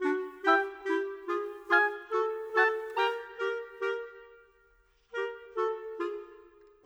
Modern 26 Winds 02.wav